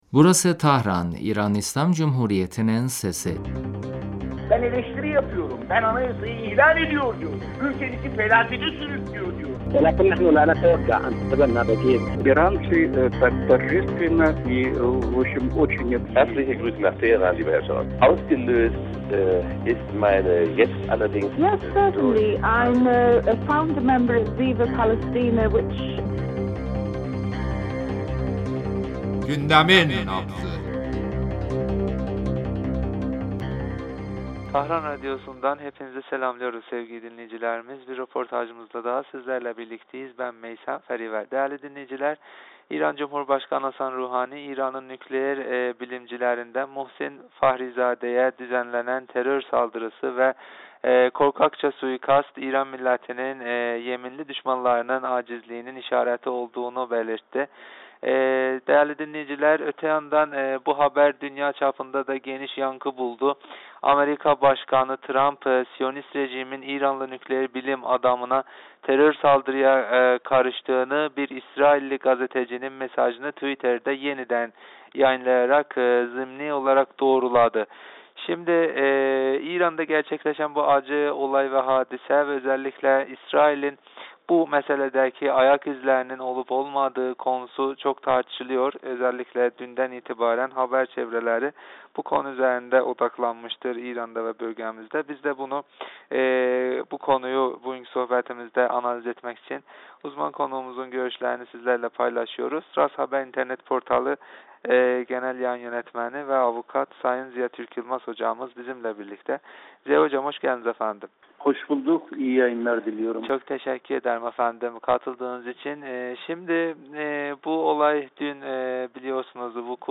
telefon görüşmesinde Tahran'da İranlı bilim adamı Fahrizade'ye yönelik gerçekleşen suikast üzerinde konuştuk.